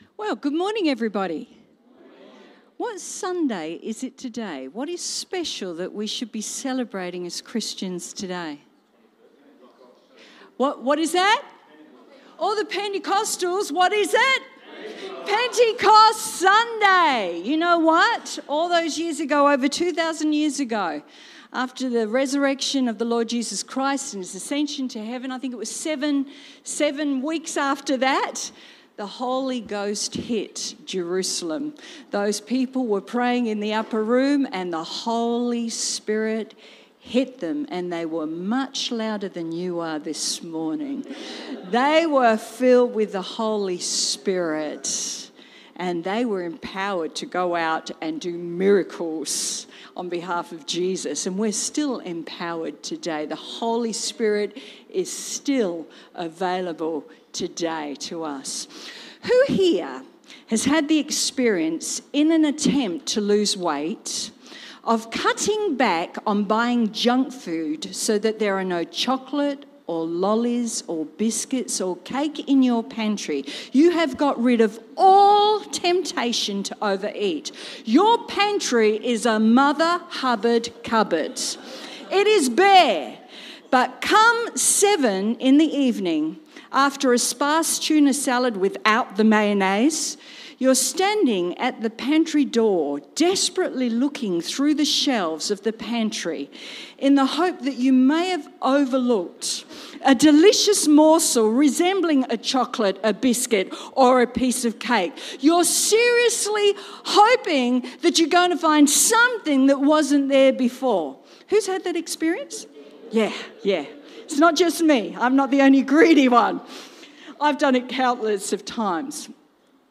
Cityview-Church-Sunday-Service-Secret-Things-Against-God.mp3